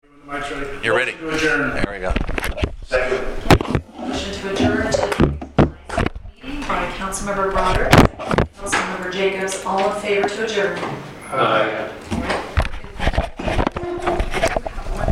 City Council Meeting - Tuesday Meeting
Meeting
All or a portion of this meeting may be held electronically to allow a council member to participate by video conference or teleconference.